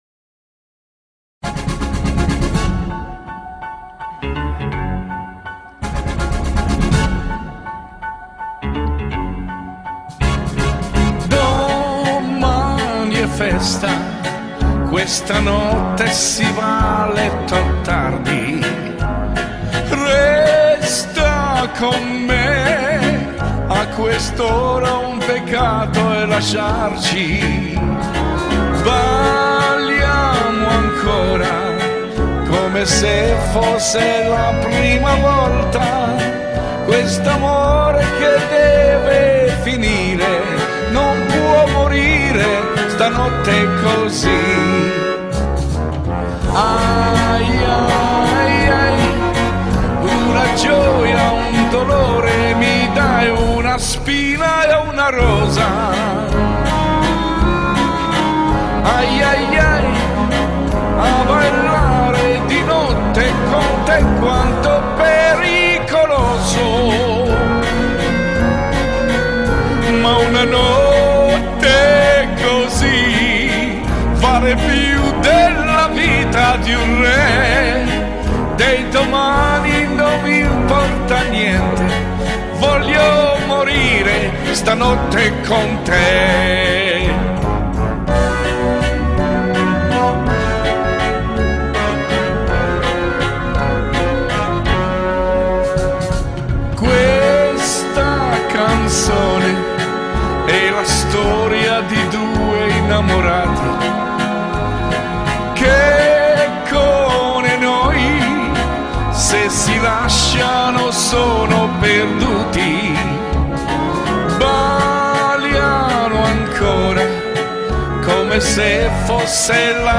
Красивый итальянский вальс